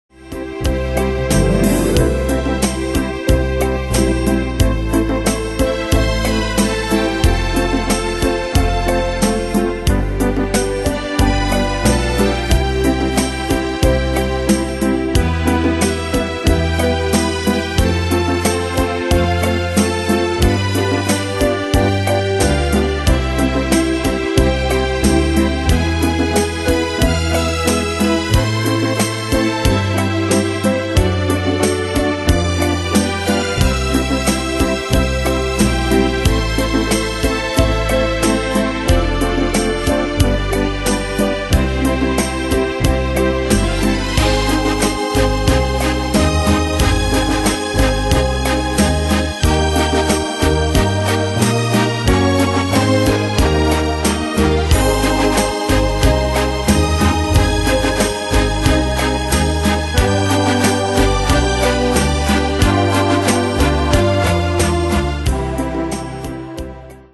Demos Midi Audio
Style: Oldies Année/Year: 1965 Tempo: 91 Durée/Time: 3.06
Danse/Dance: Rhumba Cat Id.
Pro Backing Tracks